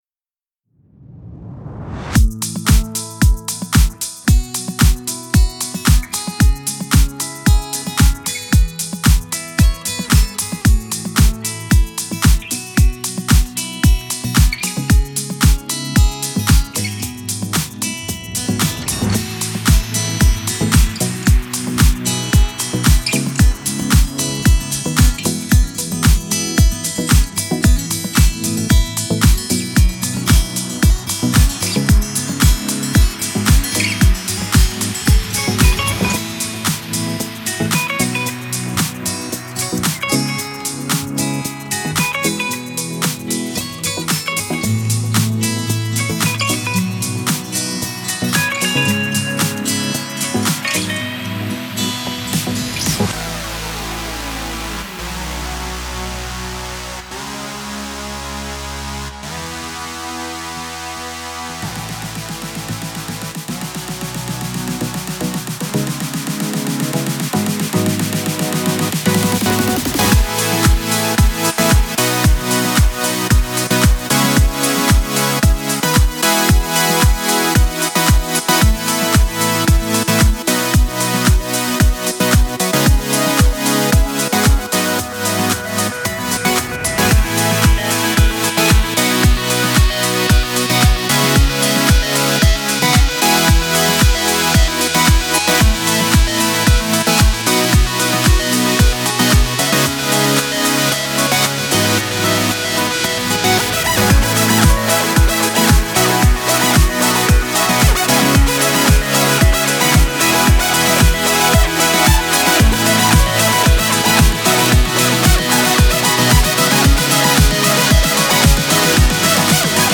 سبک الکترو آکوست